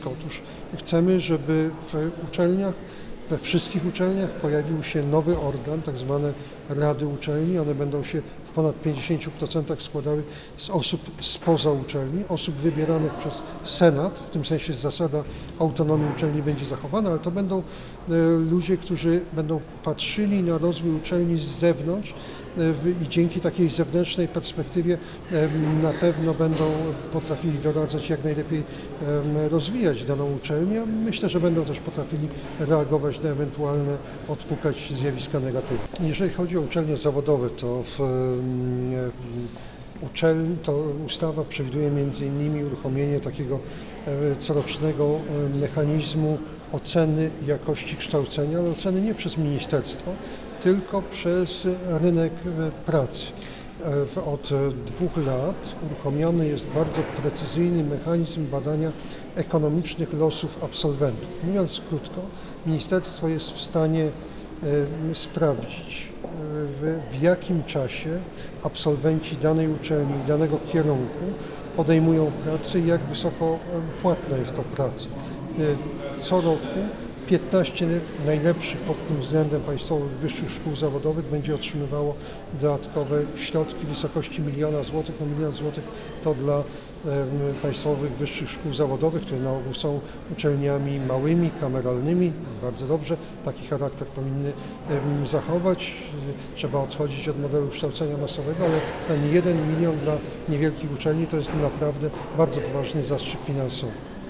– Uczelnie zawodowe mają swoją unikalną, bezcenną misję – kształcą na rzecz lokalnego rynku pracy i są miejscem tworzenia się elit społecznych – dodał podczas briefingu prasowego.